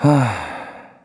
Kibera-Vox_Sigh_kr.wav